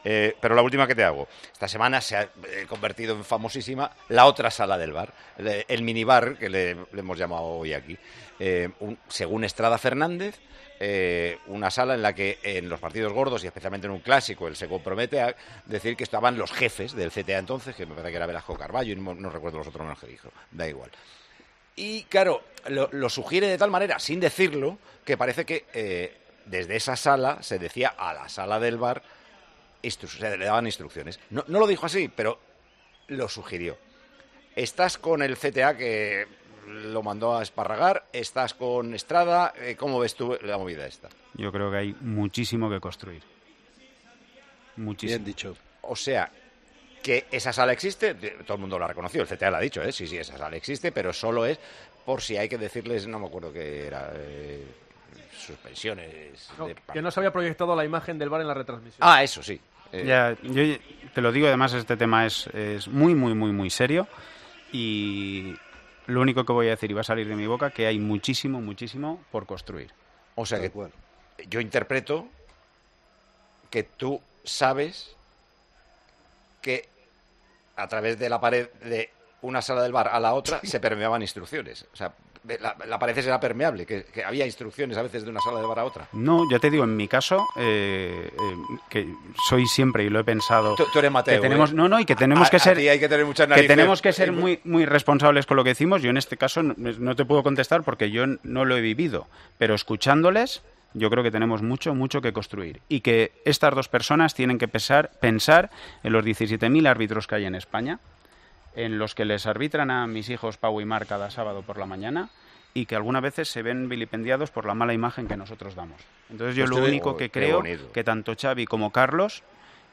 El comentarista de Tiempo de Juego le contó a Paco González lo que sabe de la supuesta sala VAR adicional a la principal.